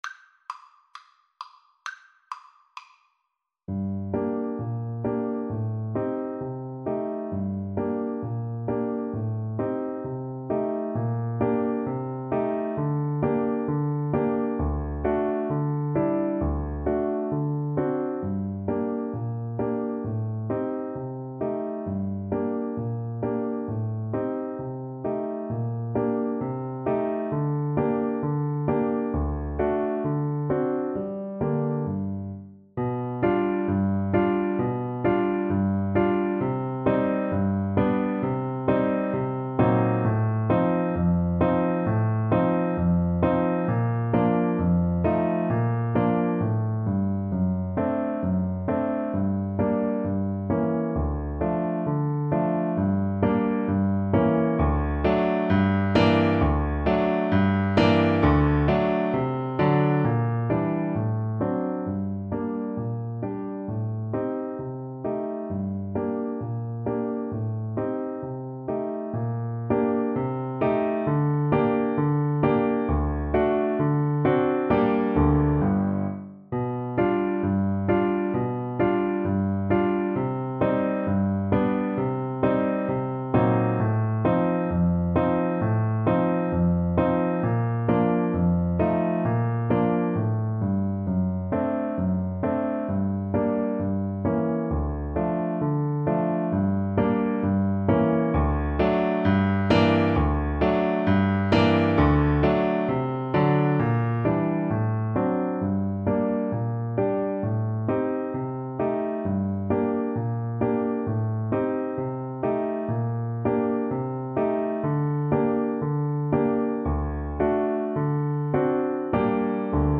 Play (or use space bar on your keyboard) Pause Music Playalong - Piano Accompaniment reset tempo print settings full screen
French Horn
~ = 132 Andantino (View more music marked Andantino)
2/2 (View more 2/2 Music)
G minor (Sounding Pitch) D minor (French Horn in F) (View more G minor Music for French Horn )
Classical (View more Classical French Horn Music)